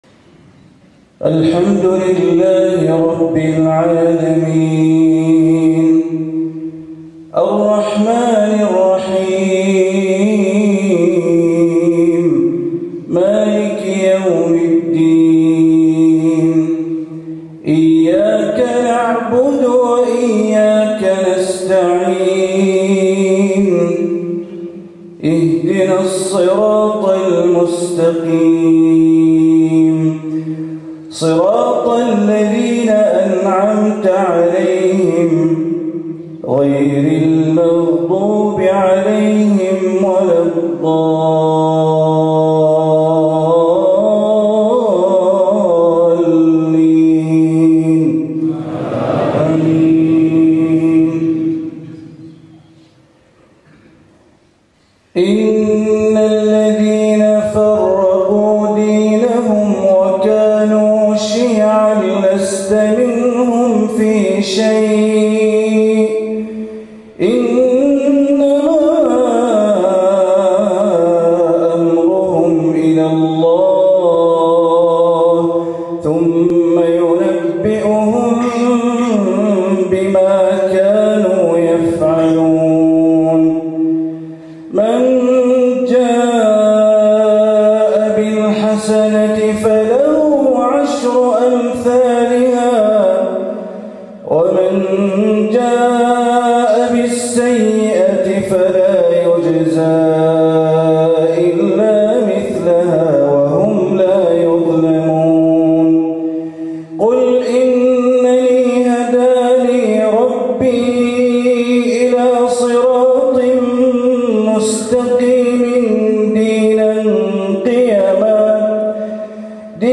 صلاتي المغرب و العشاء ١٢ ذو القعدة ١٤٤٦ لفضيلة الشيخ بندر بليلة في بريشتينا عاصمة كوسوفو > زيارة الشيخ بندر بليلة الى جمهورية كوسوفو > المزيد - تلاوات بندر بليلة